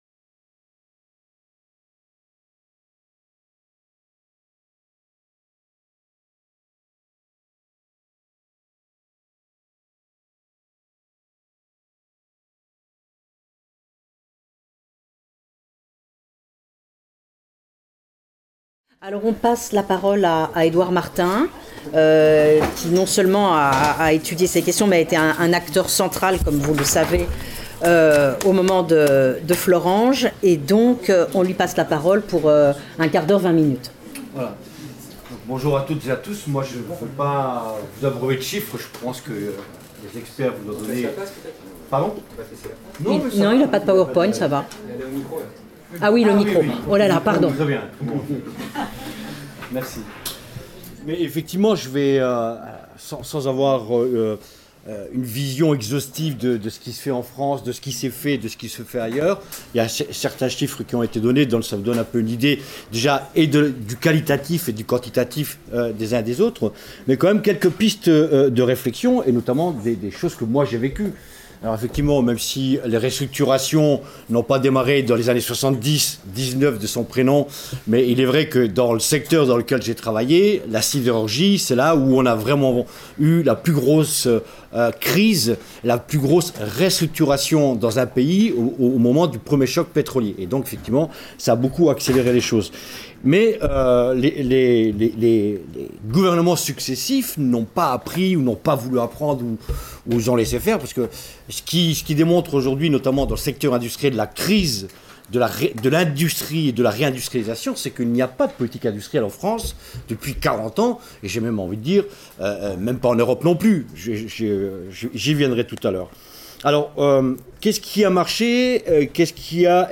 Séminaire animé par Dominique Méda et Aurore Lalucq